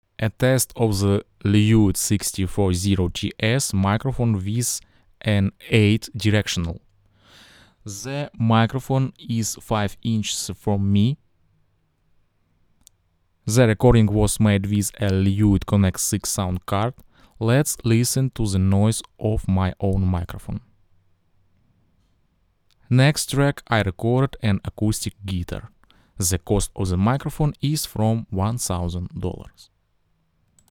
The sound of the Lewitt LCT 640 TS is open, detailed and surprisingly accurate.
The recordings are unprocessed, at the same Gain level.
Lewitt LCT 640 TS – figure eight: